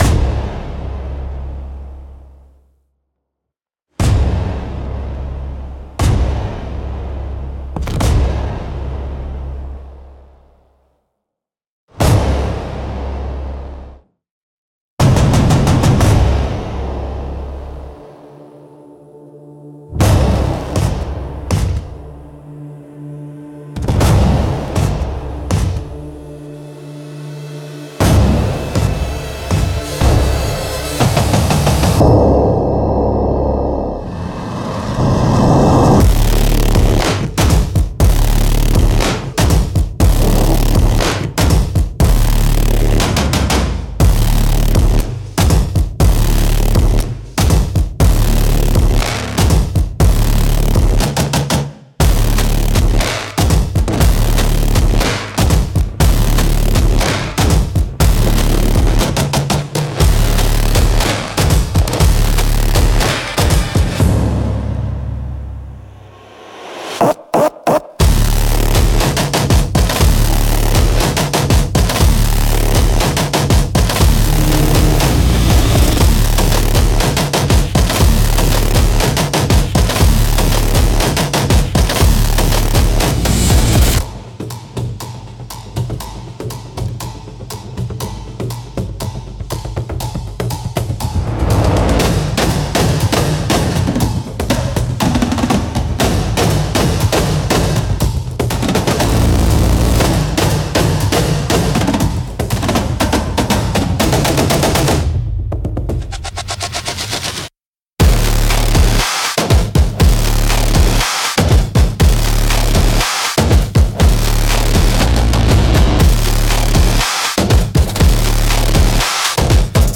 Instrumental - The Algorithm Dances - 2.44